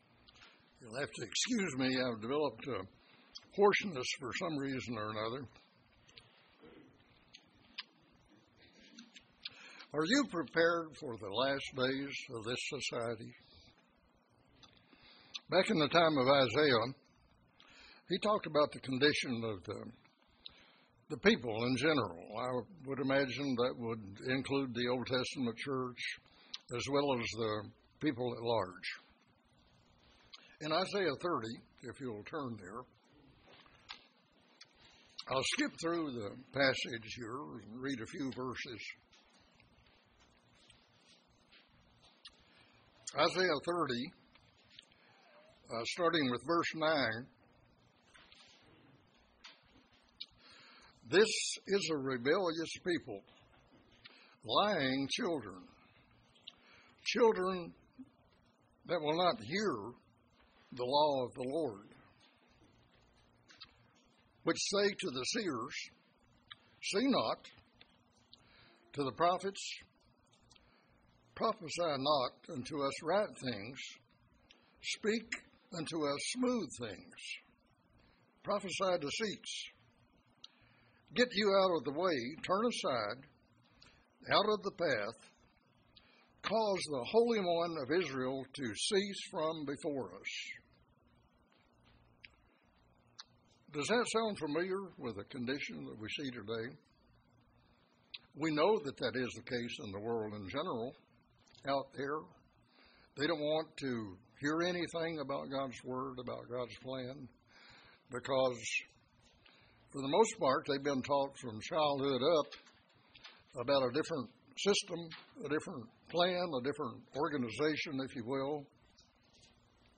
In this sermon, the reality of not so pleasant events are in store for all mankind. We like to hear of the "smooth" things, but this message brings us the importance of listening to the reality of things to come.
Given in Paintsville, KY